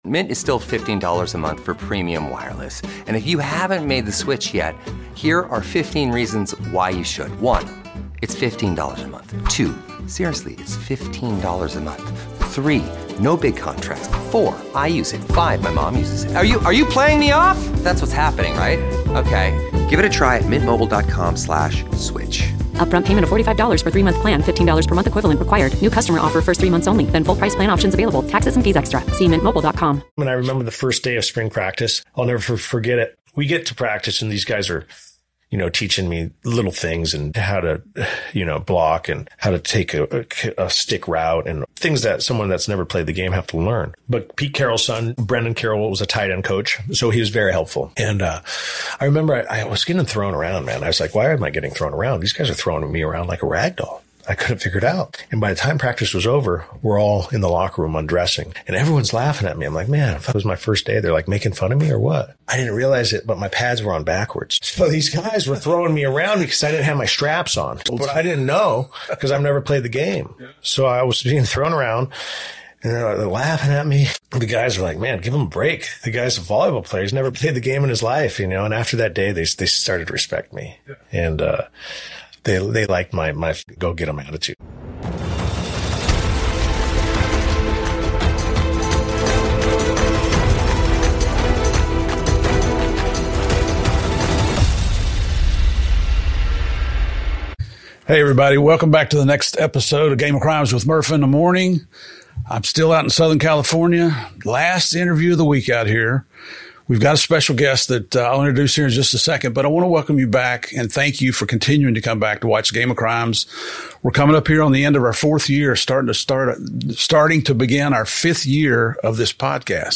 This conversation digs deep into themes of personal responsibility, the power of choices, and the thin line between success and self-destruction. It’s a rare insider’s perspective on life lived on both sides of the law—full of lessons, regrets, and powerful truths.